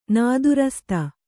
♪ nādurasta